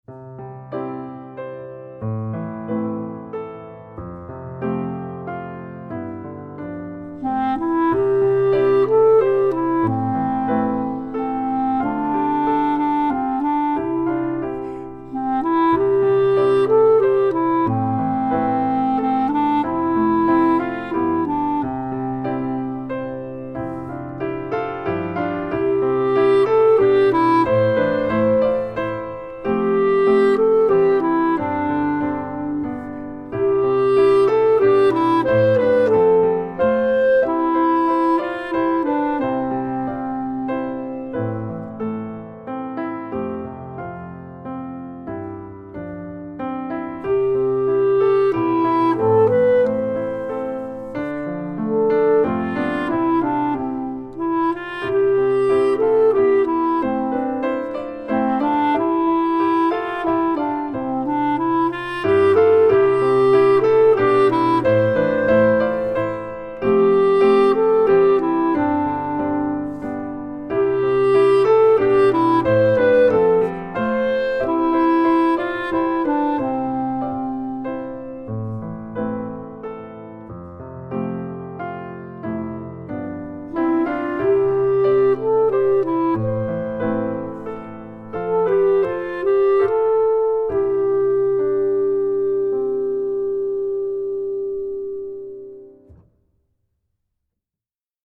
Voicing: Instrument and Piano